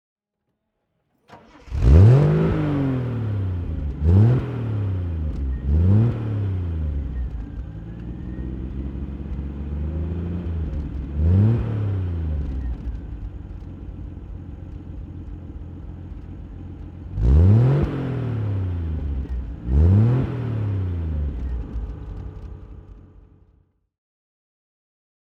Triumph 2.5 PI Estate (1972) - Starten und Leerlauf